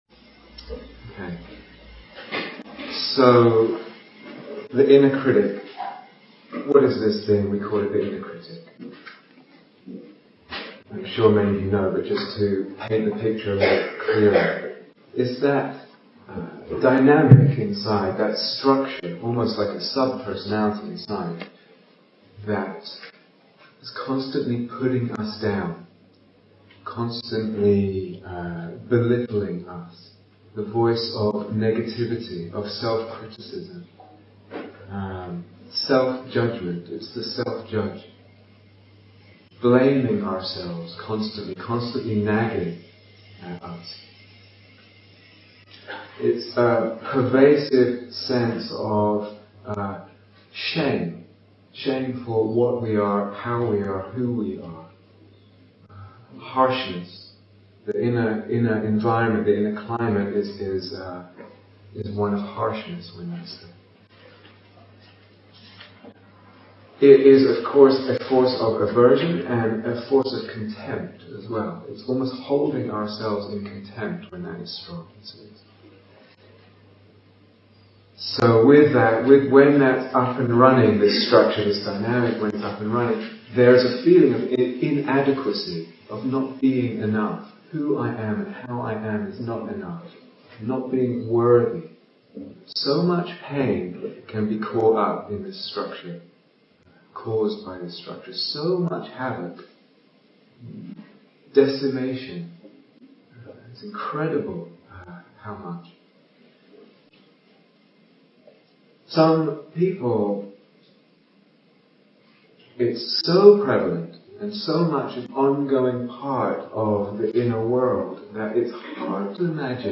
Ending the Inner Critic (1) Listen to AI-enhanced audio (noise reduced) Download 0:00:00 34:44 Date 4th December 2010 Retreat/Series Day Retreat, London Insight 2010 Transcription So, the inner critic.